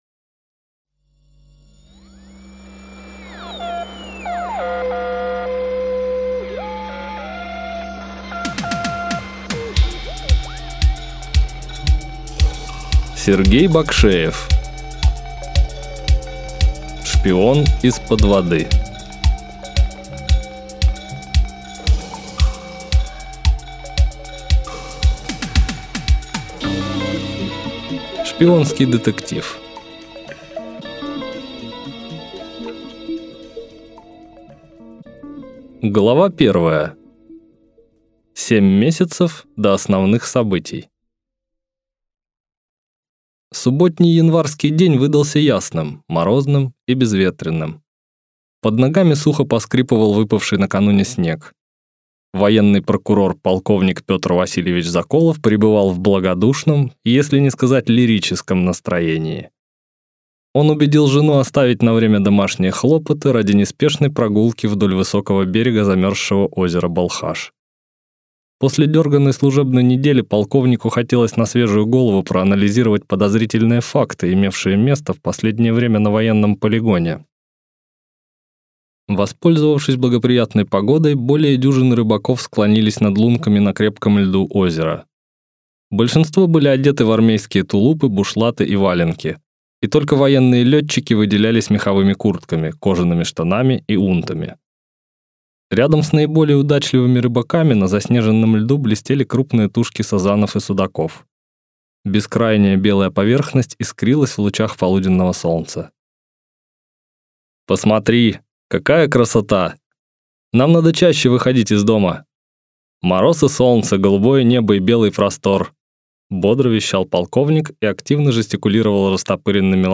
Аудиокнига Шпион из-под воды | Библиотека аудиокниг